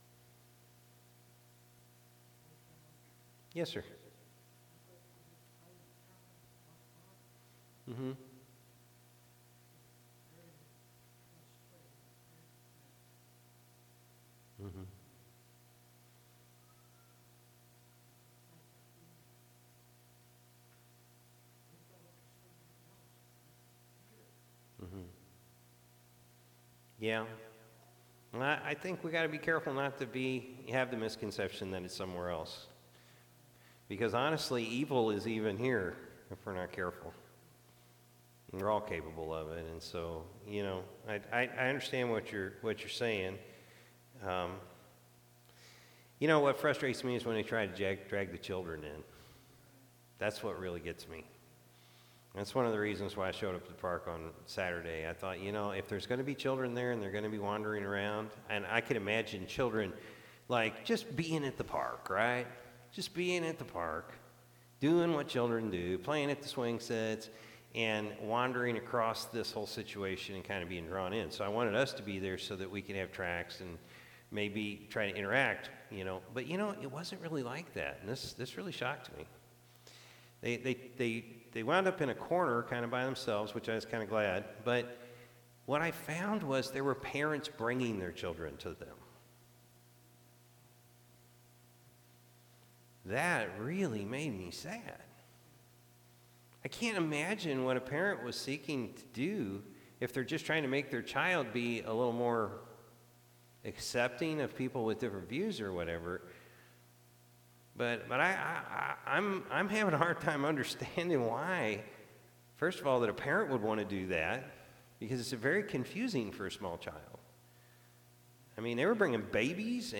June-9-2024-Evening-Service.mp3